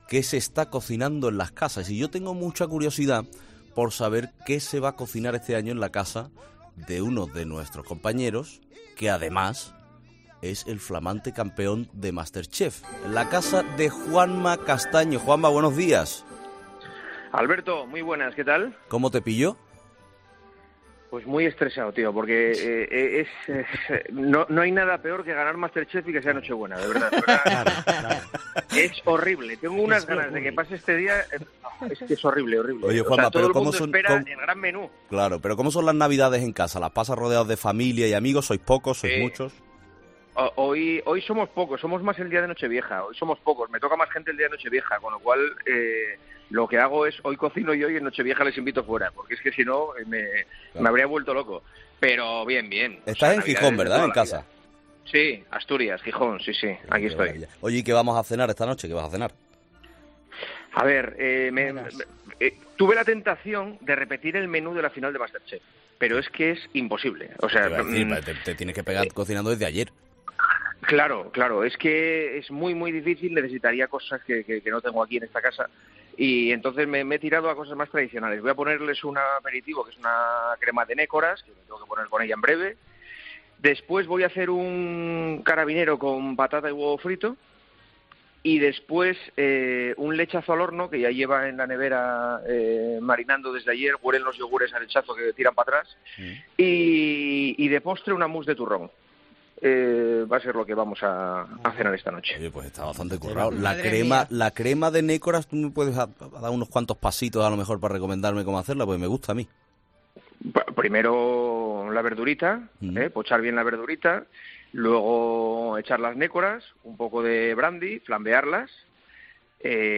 Allí hemos hablado con Juanma Castaño, presentador de El Partidazo de COPE y ganador de Masterchef, y con Joaquín Sánchez, jugador y capitán del Real Betis Balompié.